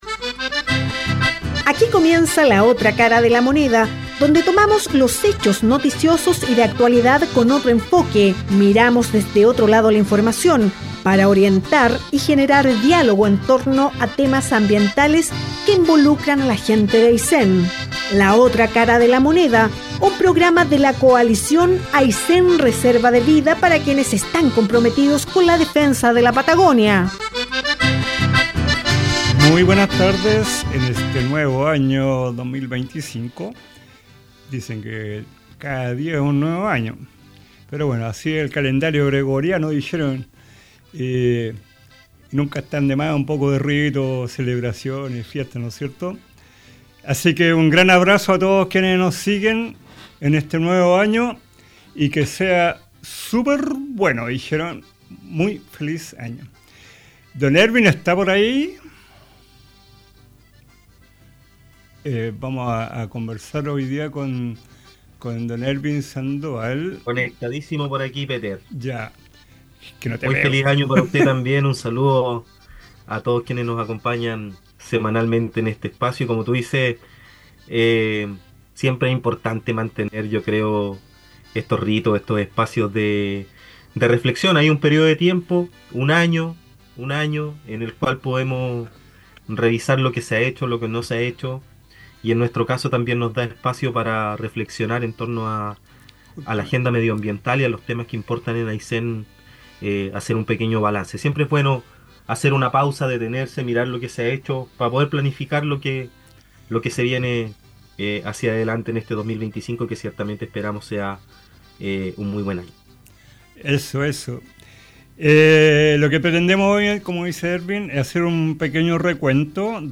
Entre los conductores del programa hacemos un recuento del año 2024, en lo cual predomino el tema marítimo, ECMPO, salmoneras, pesca, ballenas, arte. También la contingencia regional, educación ambiental, incidencia en crisis climática.